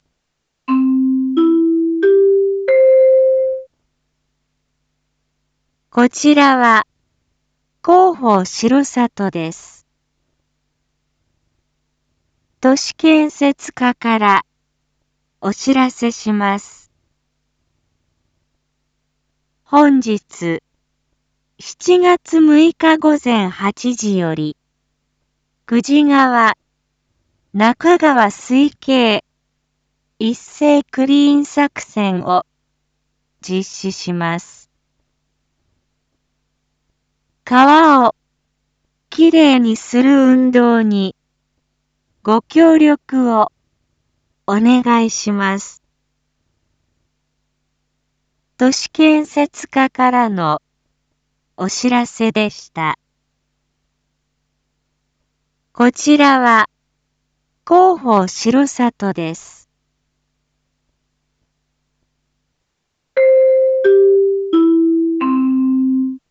Back Home 一般放送情報 音声放送 再生 一般放送情報 登録日時：2025-07-06 07:01:08 タイトル：クリーン作戦（R7.7.6実施） インフォメーション：こちらは、広報しろさとです。